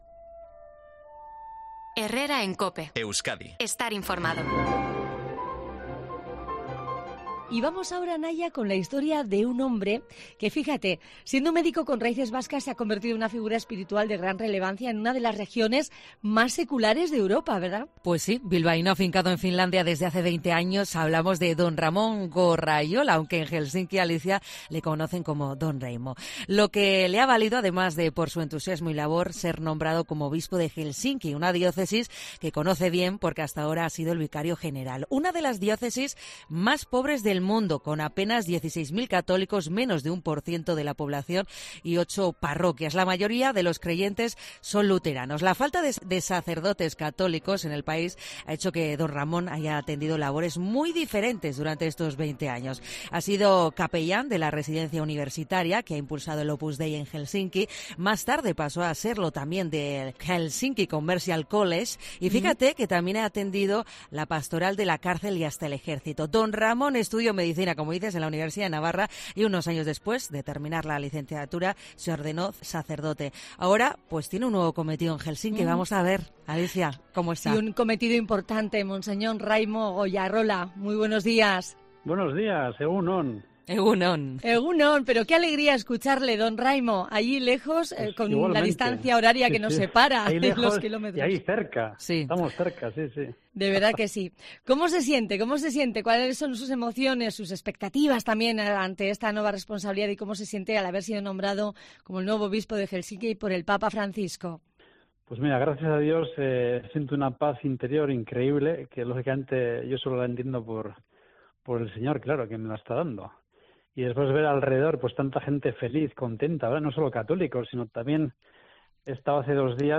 Entrevista al Obispo de Helsinki, Ramón Goyarrola, en COPE Euskadi